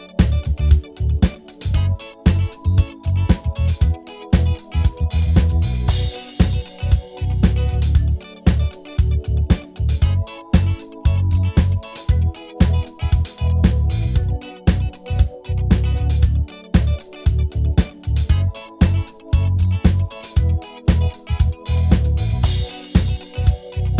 Minus Lead Guitar Pop (2010s) 4:17 Buy £1.50